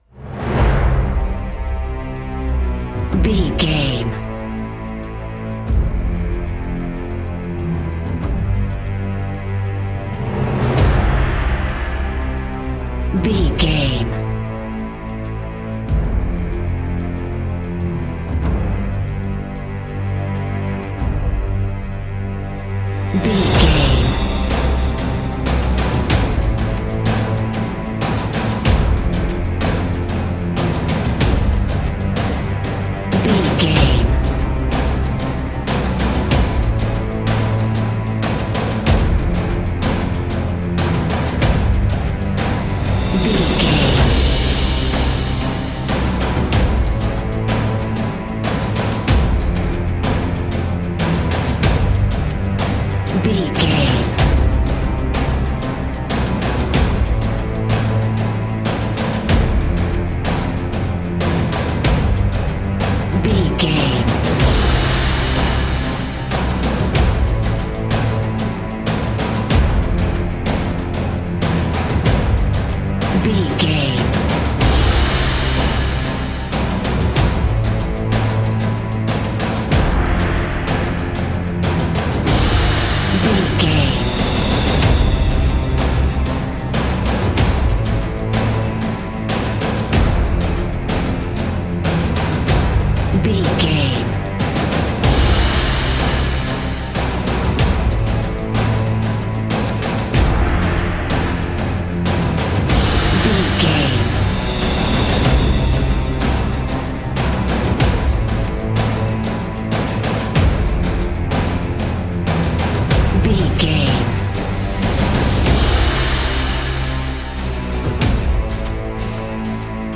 Uplifting
Ionian/Major
energetic
brass
cello
choir
drums
piano
strings
trumpet
violin